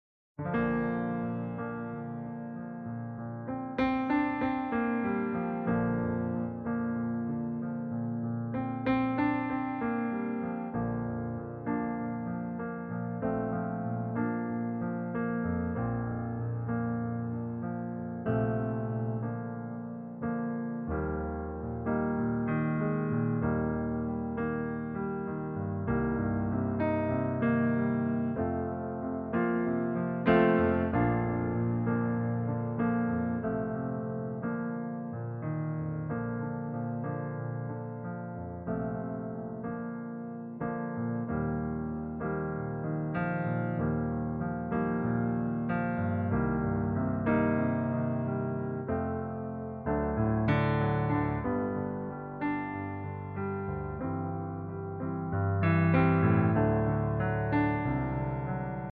Demo in Bb-Dur